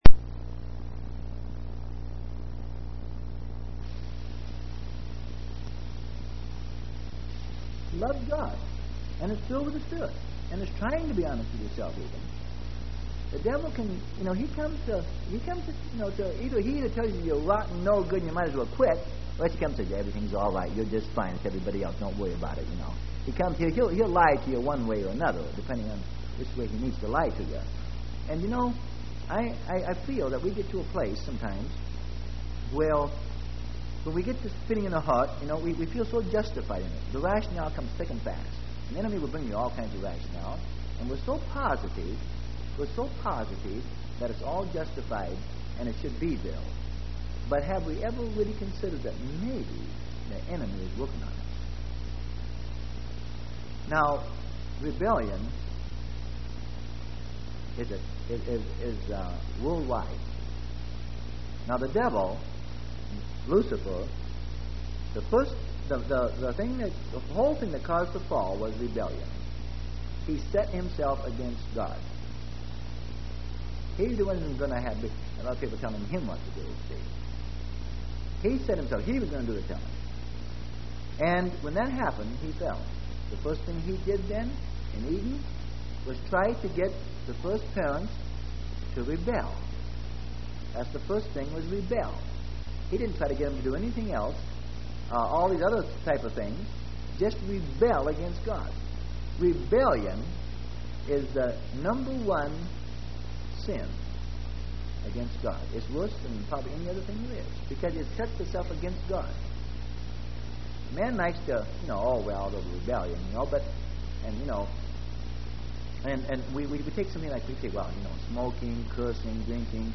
Sermon: Rebellion, Submission, And Appearance.